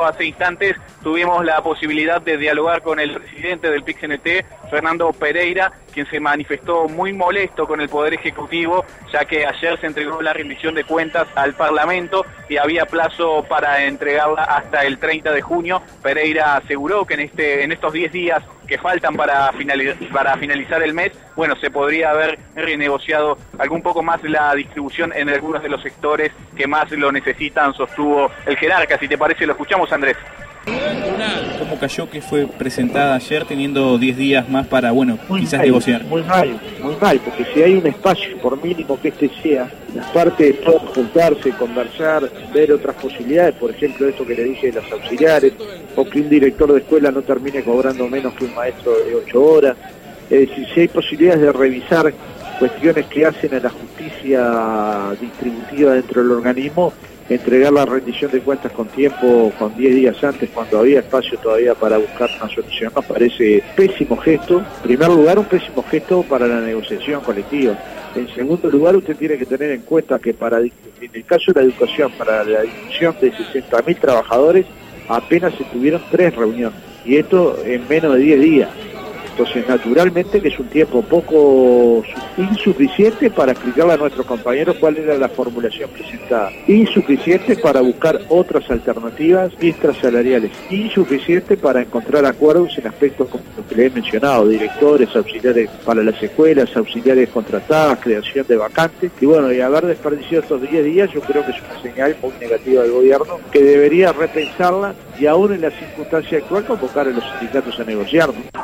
En la movilización convocada por el PIT CNT, el móvil de Informativo Universal dialogó con Fernando Pereira quien manifestó la molestia de la central sindical con el Poder Ejecutivo al enviar la Rendición de cuentas al Parlamento sin agotar los diez días de plazo que aún tenía constitucionalmente para seguir dialogando con los sindicatos. No les consultaron antes para intentar renegociar algunos rubros y lo consideran un «pésimo gesto» del Ejecutivo.